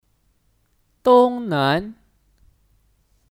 东南 Dōngnán : Tenggara